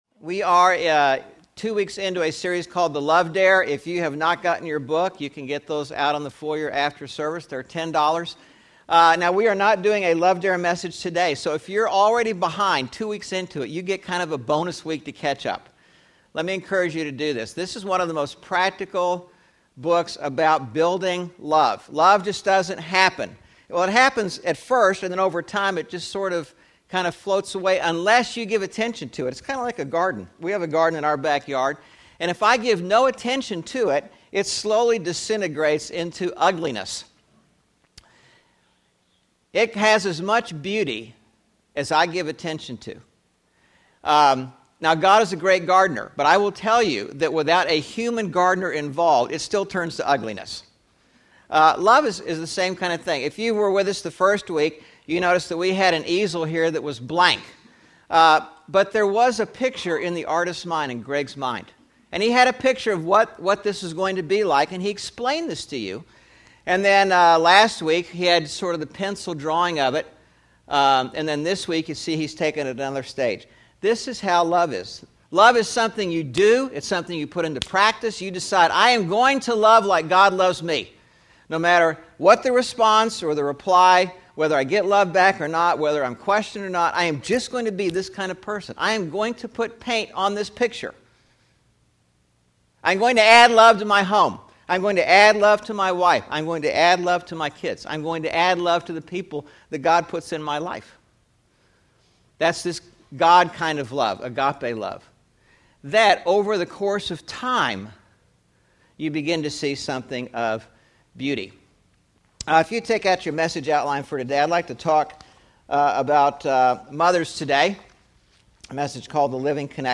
5/08/11 Sermon (Mother’s Day > The Honor of Motherhood) – Churches in Irvine, CA – Pacific Church of Irvine